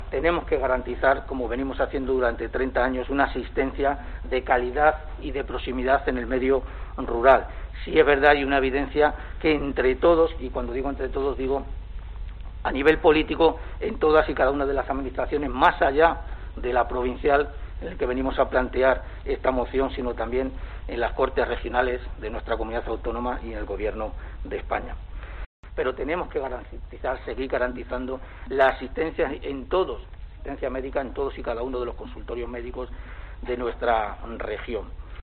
El portavoz del equipo de Gobierno, Juan Carlos Sánchez Mesón ---sanidad